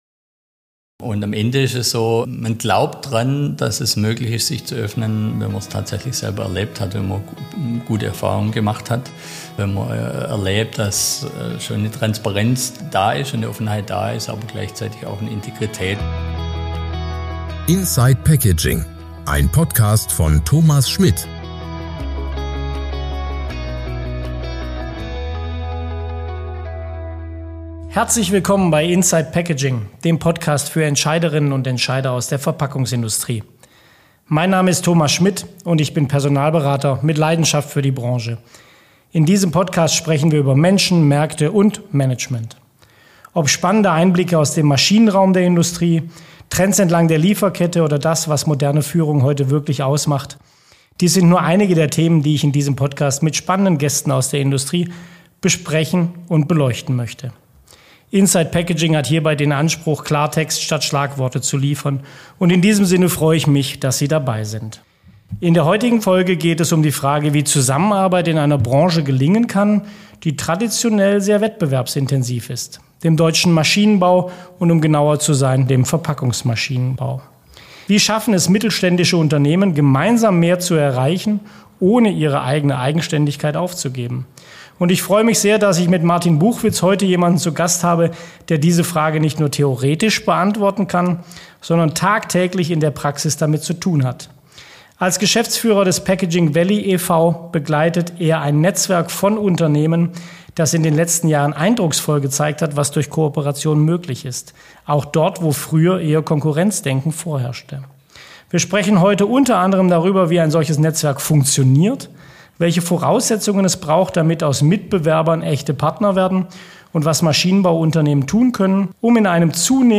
Ein Gespräch über Kultur, Netzwerke und die Zukunftsfähigkeit einer Schlüsselbranche.